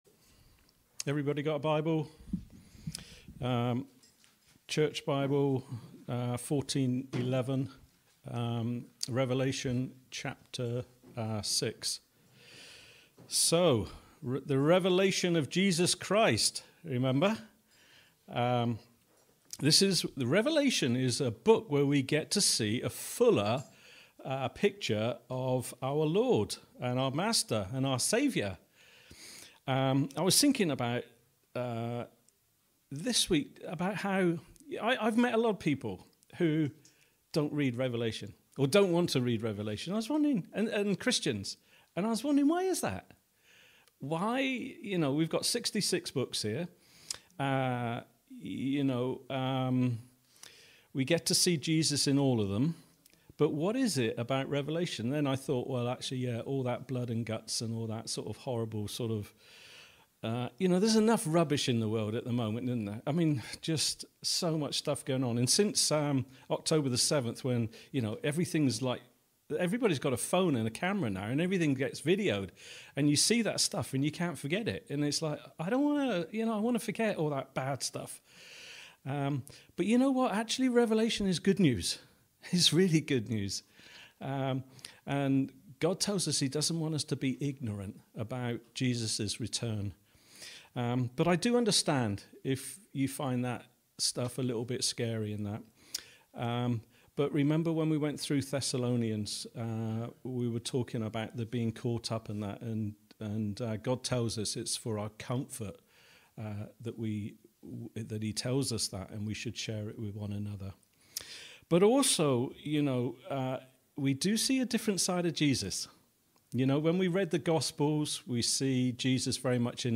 A message from the series "Thessalonians."